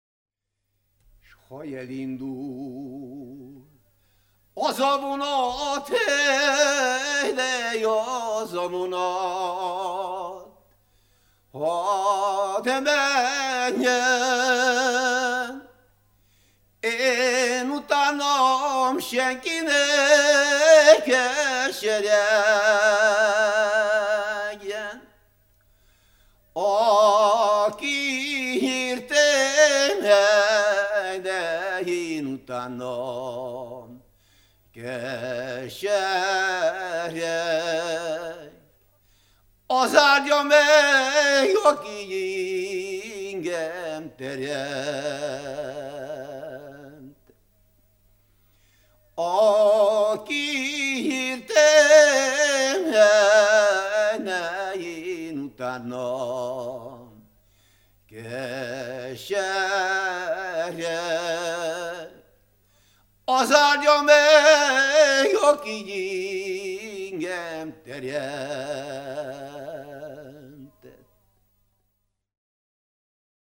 ének
Nádas mente (Kalotaszeg, Erdély)